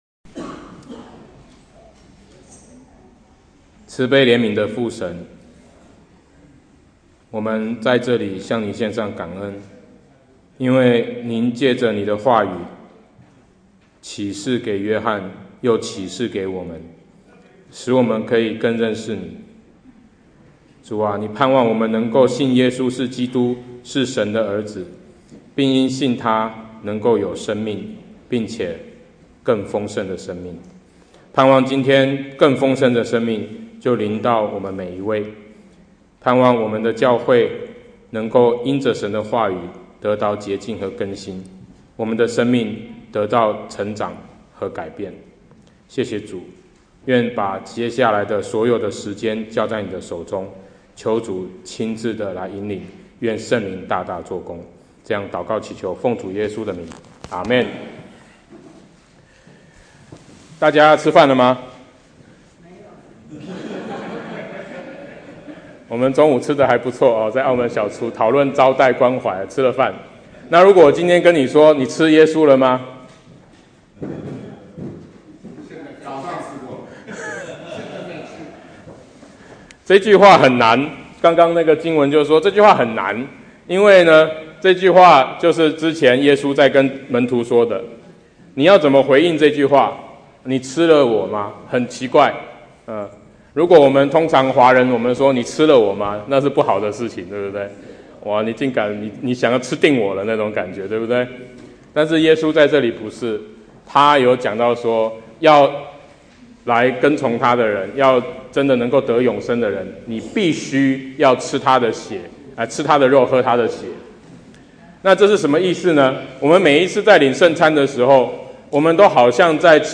01/04/2018 經文: 路加福音24：1-12 證道系列